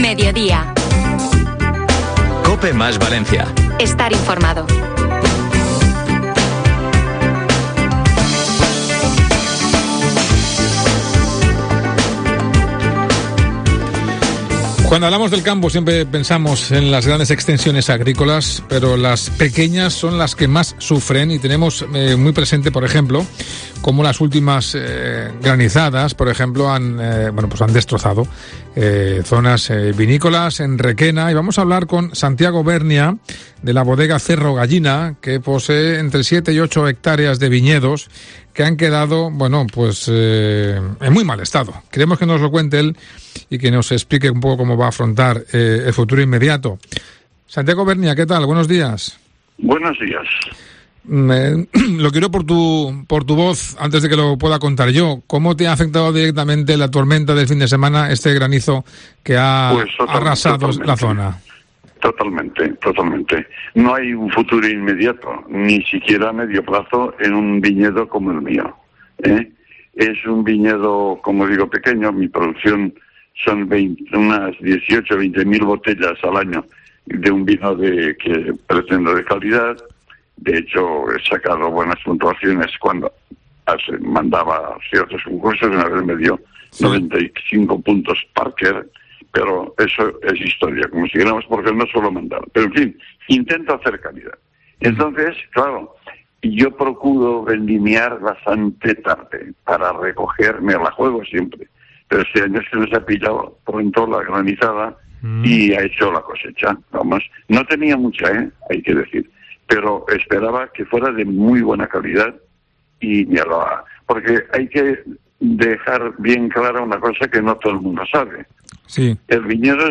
ENTREVISTA | Un viticultor valenciano cuenta a COPE cómo ha visto arrasada su cosecha por el granizo